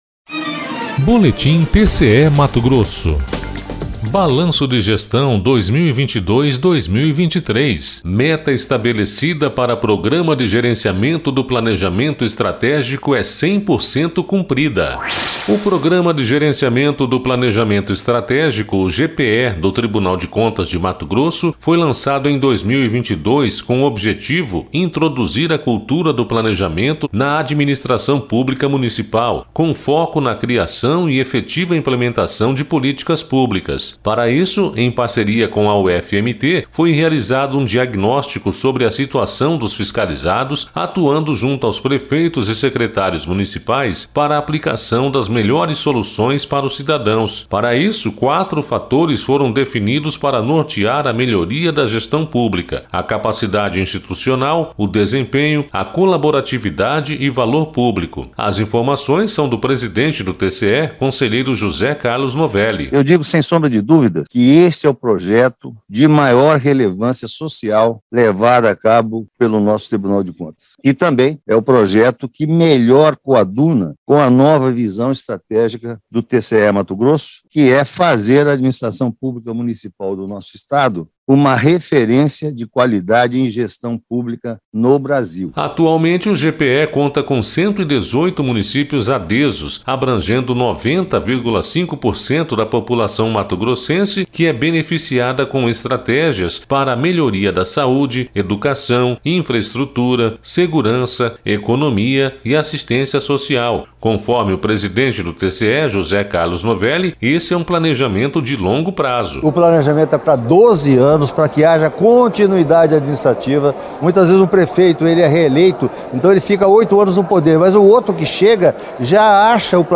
Sonora: José Carlos Novelli - conselheiro-presidente do TCE-MT